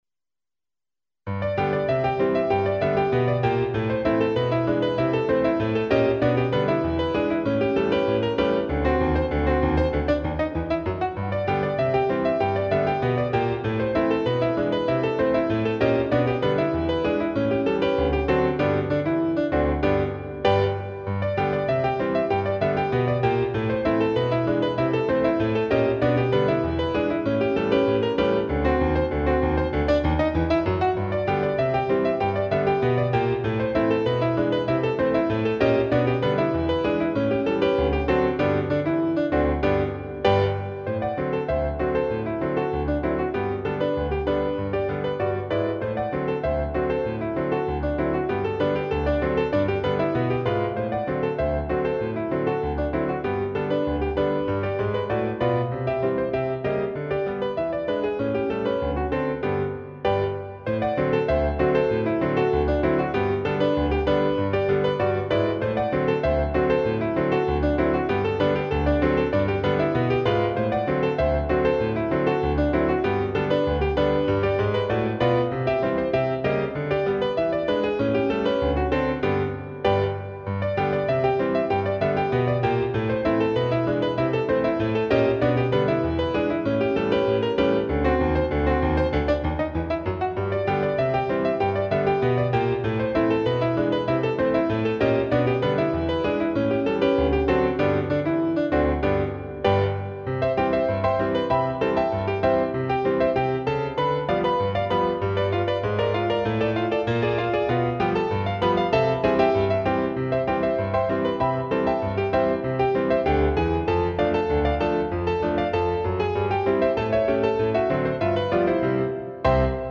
I made it with "Miroslav Philharmonik"
RAGTIME MUSIC